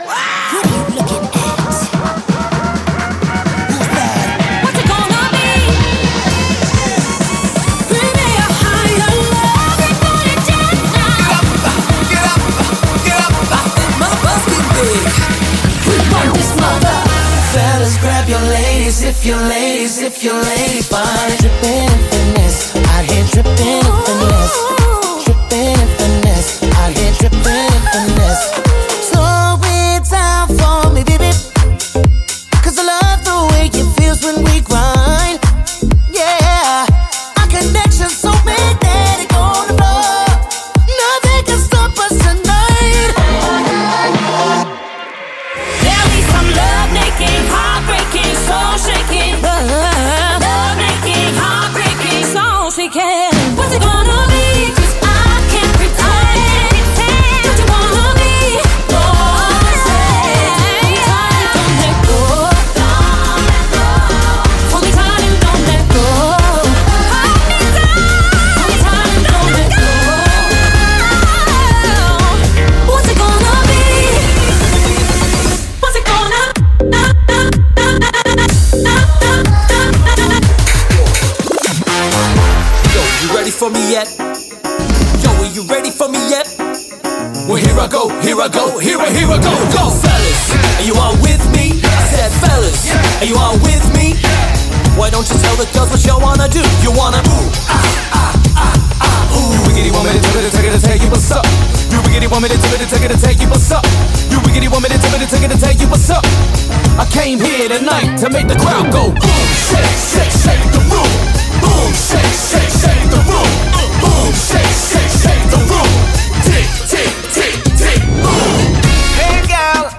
• Iconic tracks remixed live for a unique musical experience
• High-energy Dance / Pop Anthems & Club Hits